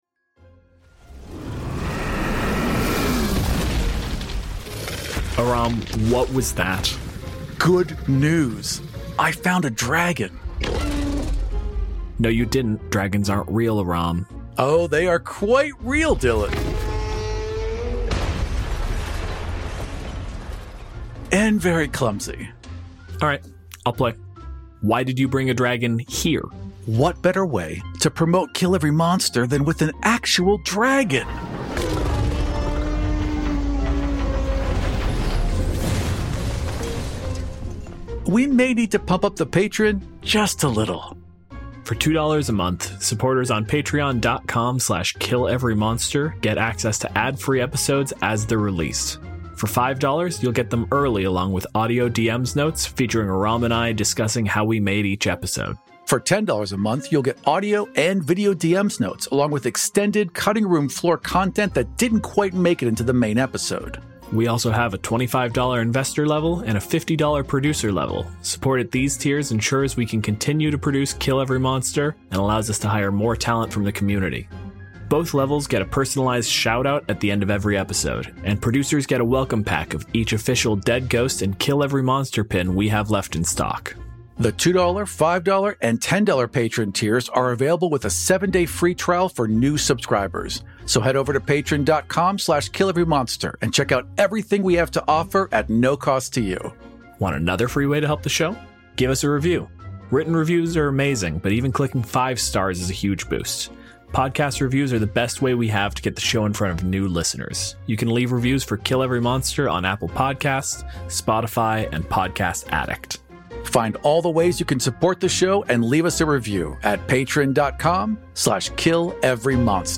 The first part is a discussion, and the second is a one-shot actual play where our guest takes on the role of the monster.